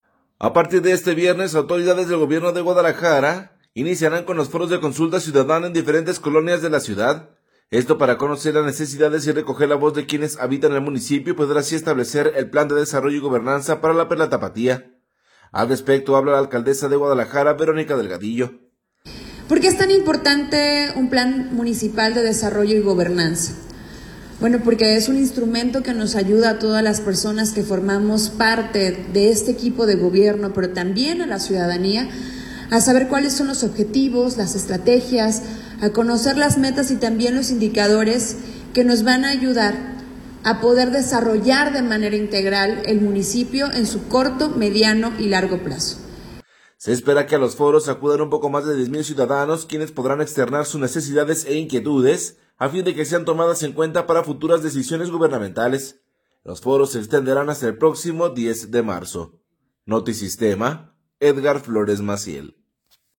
A partir de este viernes autoridades del gobierno de Guadalajara iniciarán con los foros de consulta ciudadana en diferentes colonias de la ciudad, para conocer las necesidades y recoger la voz de quienes habitan el municipio y poder así establecer el plan de Desarrollo y Gobernanza para La “Perla Tapatía”. Al respecto, habla la alcaldesa de Guadalajara, Verónica Delgadillo.